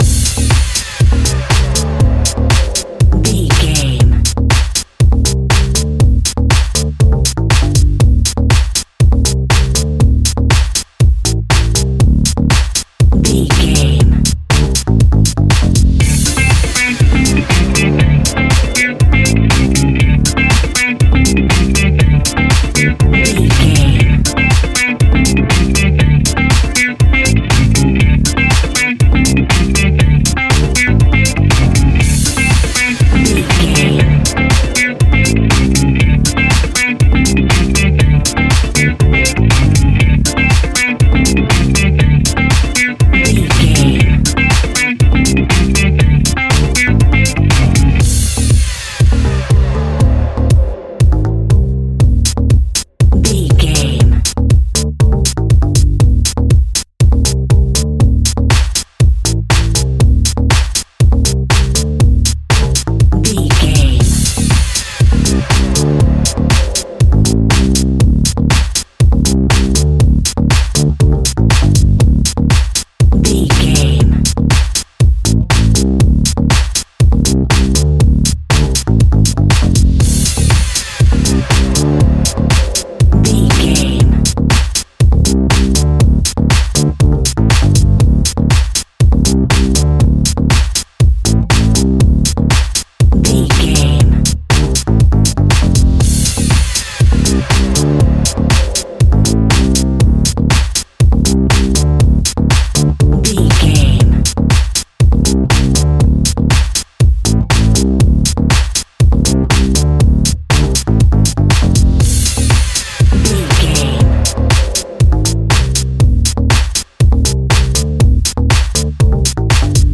Aeolian/Minor
D
groovy
futuristic
hypnotic
uplifting
bass guitar
synthesiser
drum machine
funky house
disco house
electronic funk
energetic
upbeat
synth leads
Synth Pads
synth bass